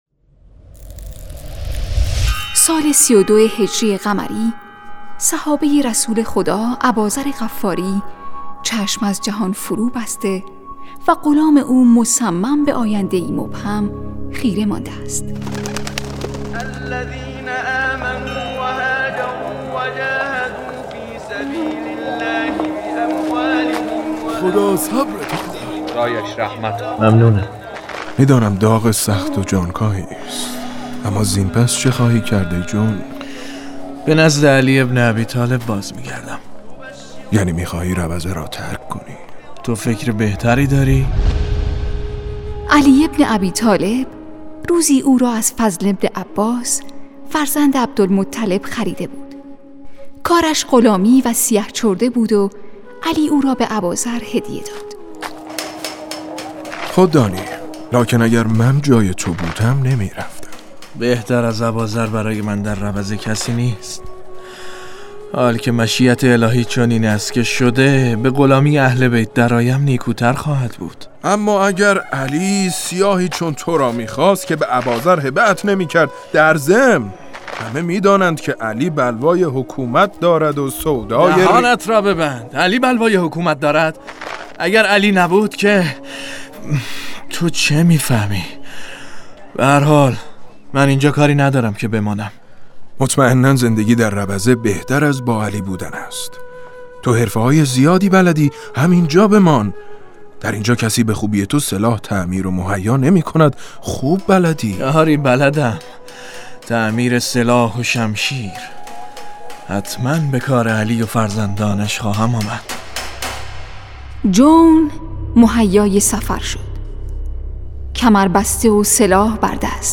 نمایش صوتی رندان تشنه لب